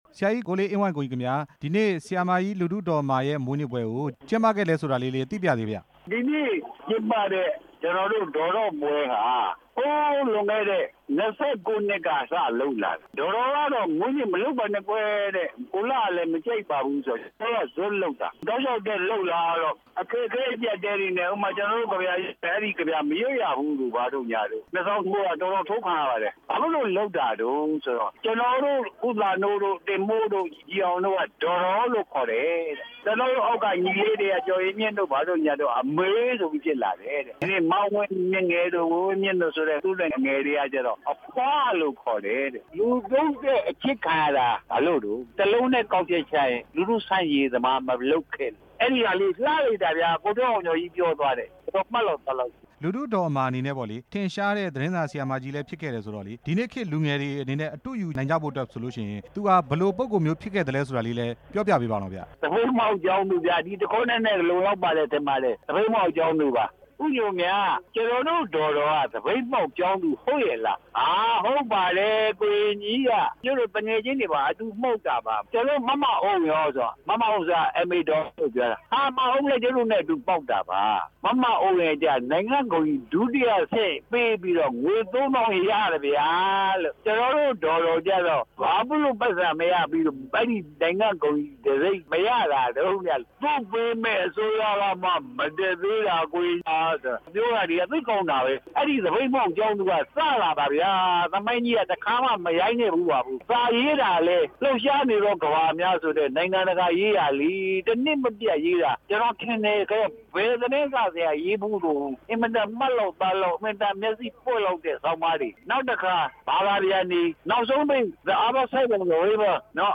ကို မေးမြန်းချက်